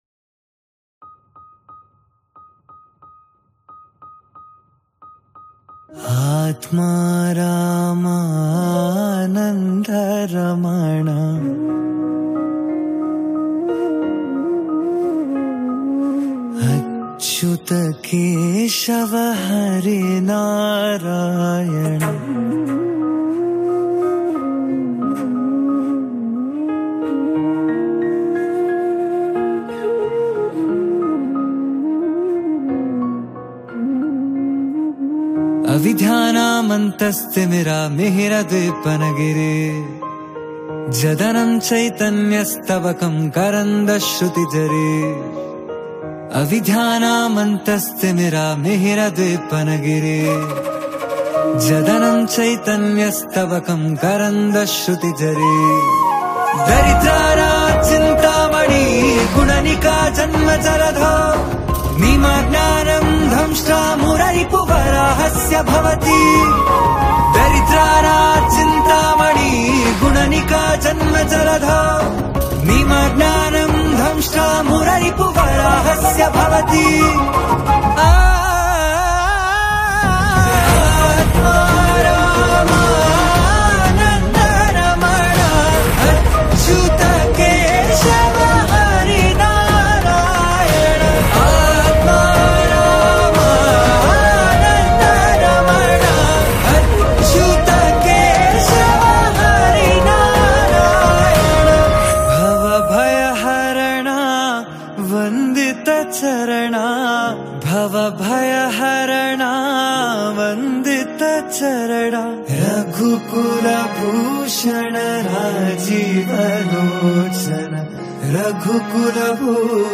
Devotional Songs